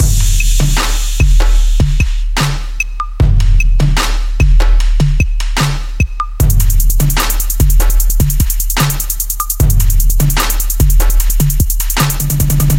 幻术鼓乐75bpm
Tag: 75 bpm Hip Hop Loops Drum Loops 2.15 MB wav Key : Unknown